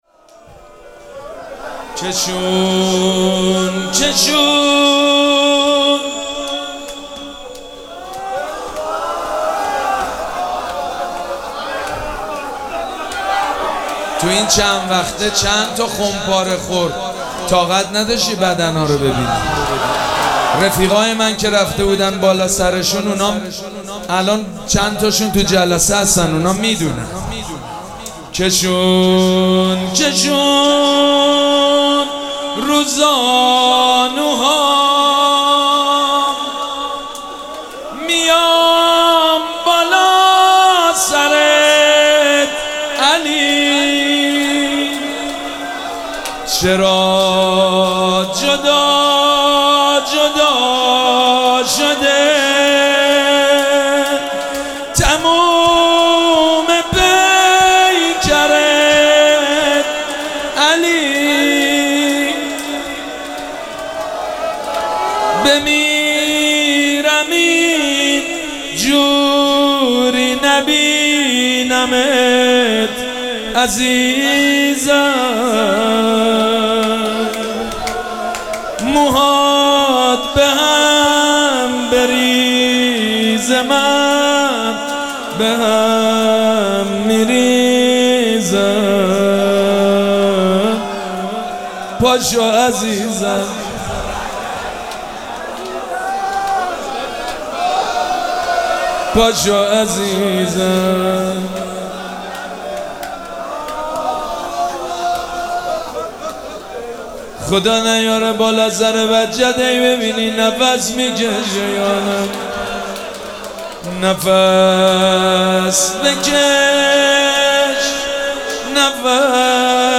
مراسم عزاداری شب هشتم محرم الحرام ۱۴۴۷
روضه